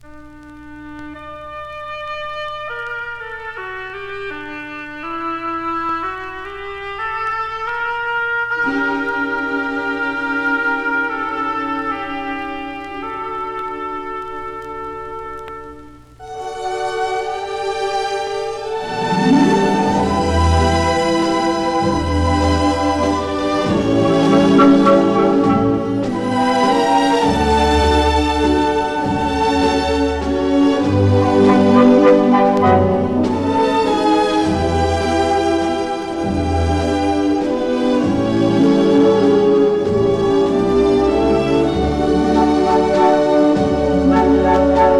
オーケストラによる美しいメロディと効果的に現れる歌声、暗さも魅力的です。
Stage & Screen, Soundtrack　USA　12inchレコード　33rpm　Mono